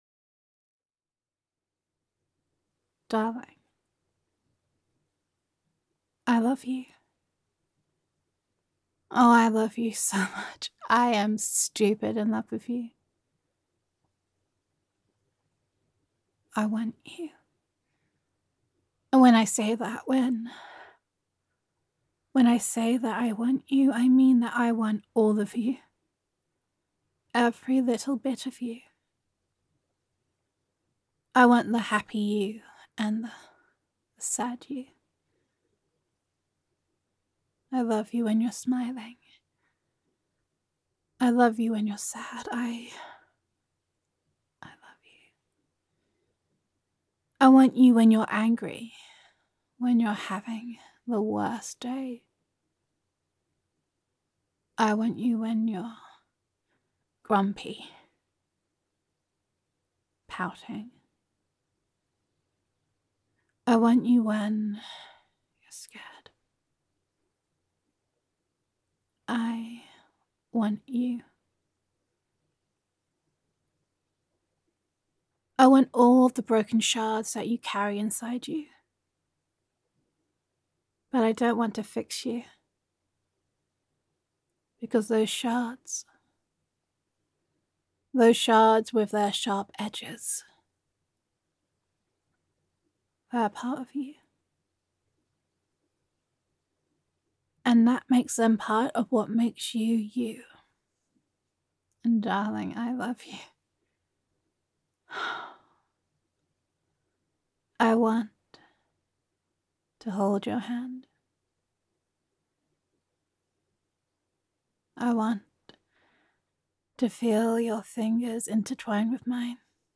[F4A] I Want You [Appreciation][Adoration][Good Days and Bad Days][Girlfriend Roleplay][Gender Neutral][Your Girlfriend Simply Adores You]